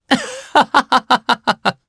Arch-Vox_Happy3_jp.wav